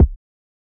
KICK IIIIIIII.wav